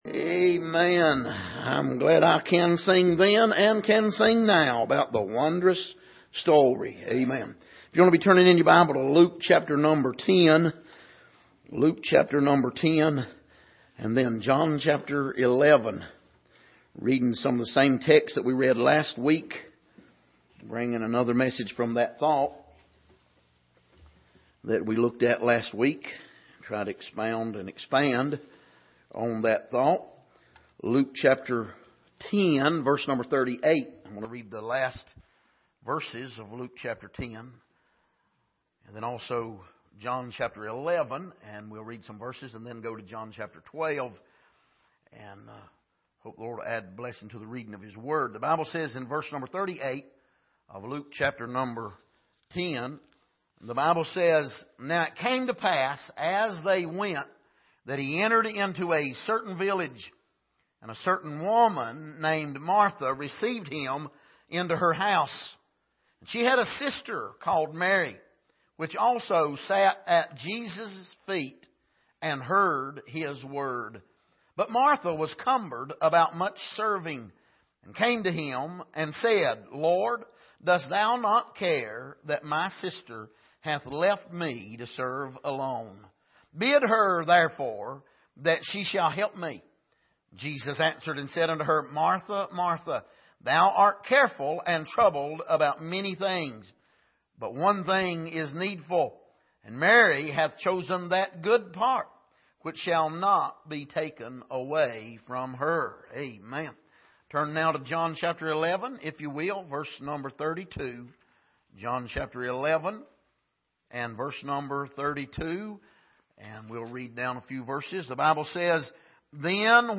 Passage: Luke 10:38-42 Service: Sunday Morning